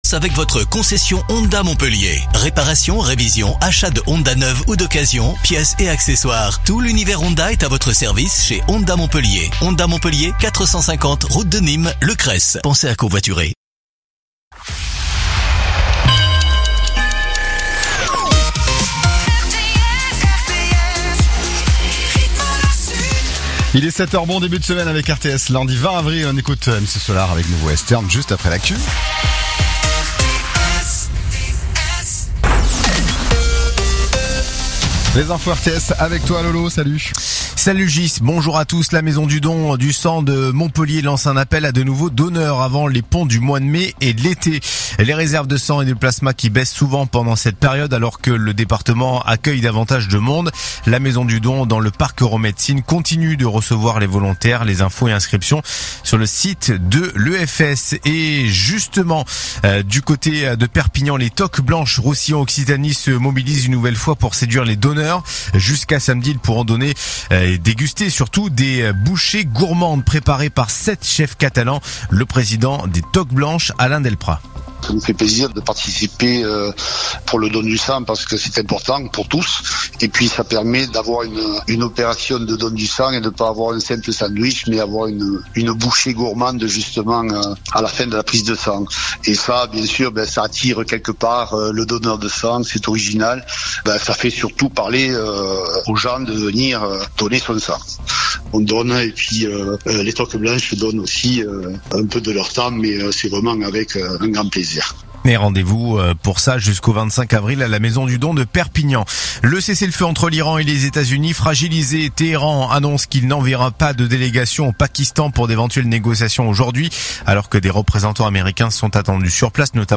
RTS : Réécoutez les flash infos et les différentes chroniques de votre radio⬦